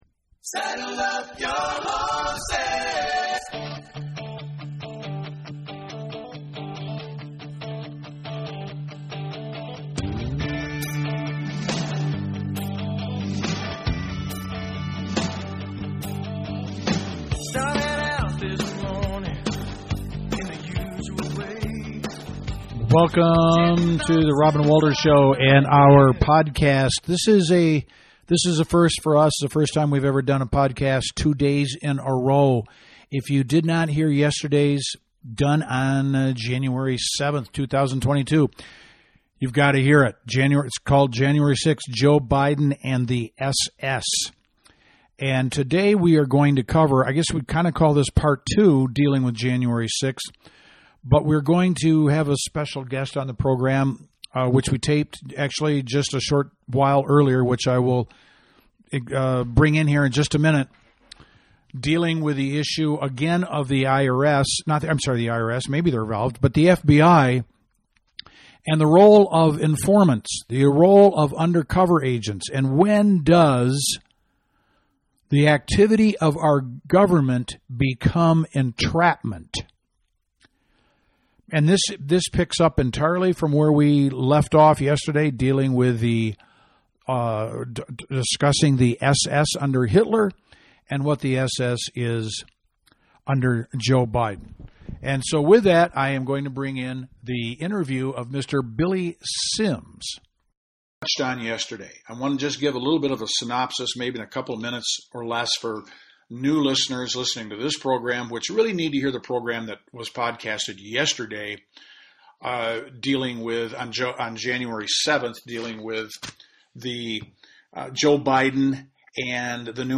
Jan.6 - Interview with Former Undercover Agent - 1/8/22